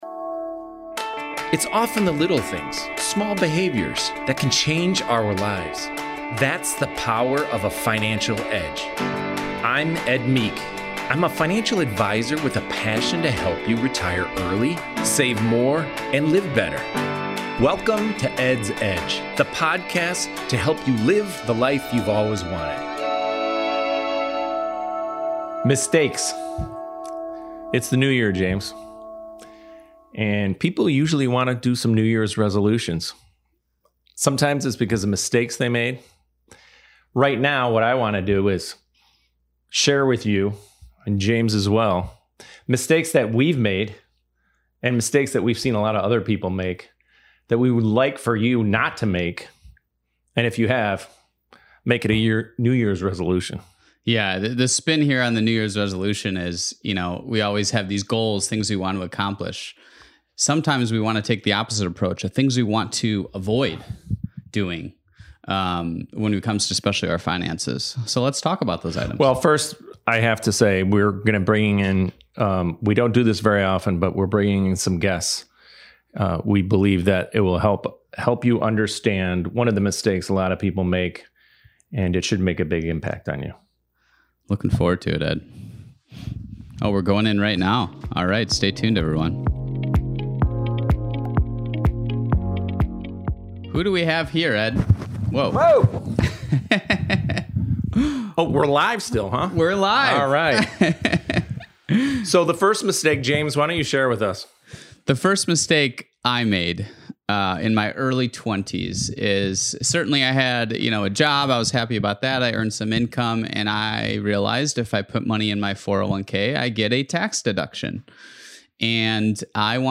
Join experienced financial advisors